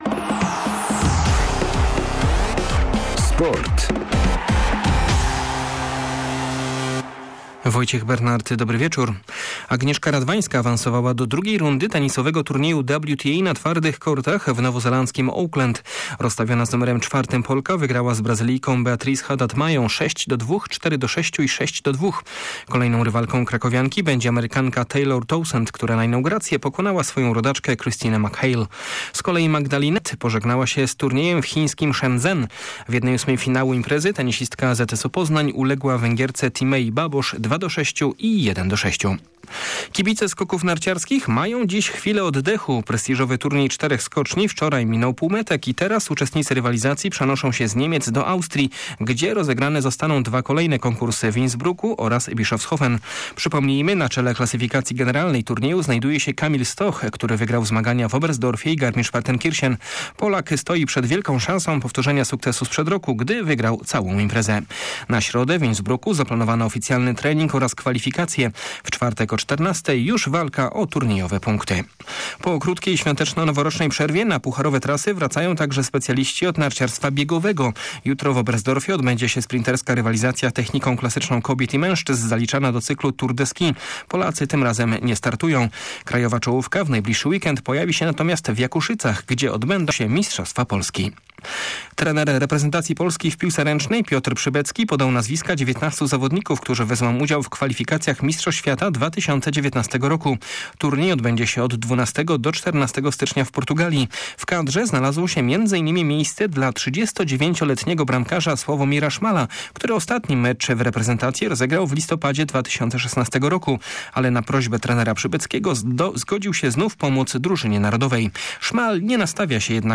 02.01 serwis sportowy godz. 19:05